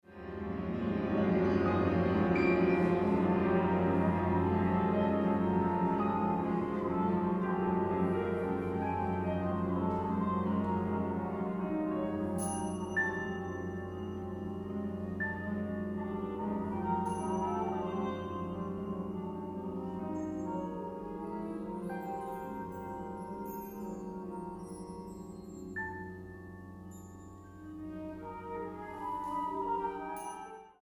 Advanced Composition Project, London College of Music
Premiere: 14 May 2002, Vestry Hall, Ealing London
Tragic Symphony